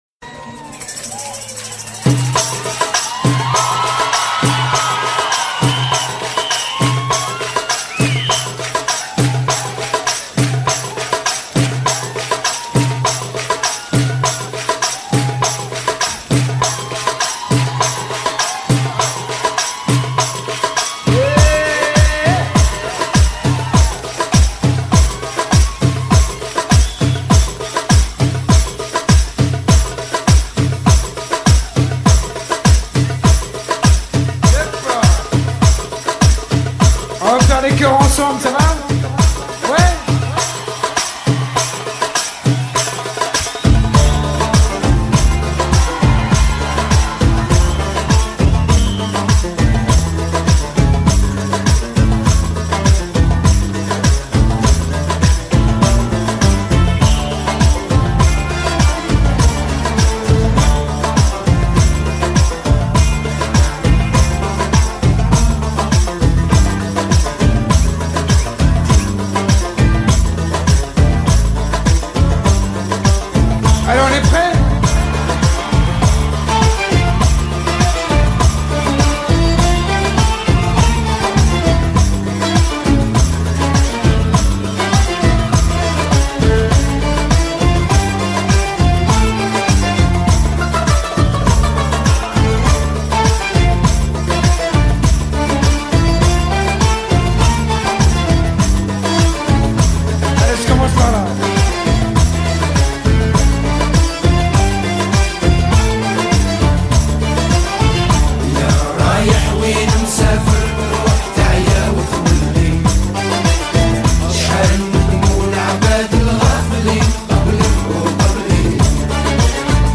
Carpeta: musica arabe mp3